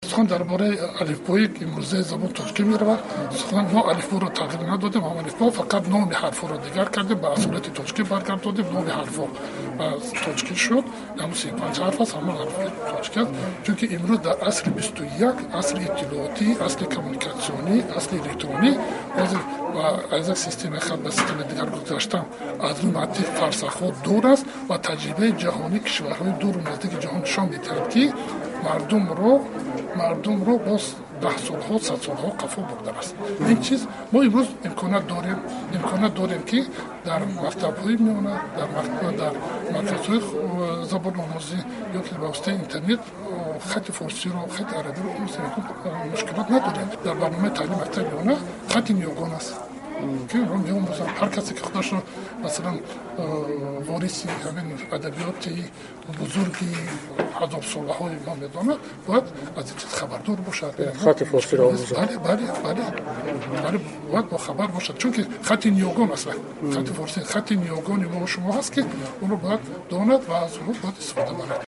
Гузоришҳои радиоӣ
Раиси Кумитаи забон ва истилоҳоти Тоҷикистон гуфт, ки мухолифи омӯзиши хатти форсӣ нестанд, вале айни замон зарурати бозгашт ба онро намебинанд. Олимҷон Муҳаммадҷонзода рӯзи 29-уми июл дар нишасти матбуотӣ гуфт, “ҳар касе худро вориси адабиёти бузурги ҳазорсола медонад, бояд хатти форисро донад ва омӯзад”.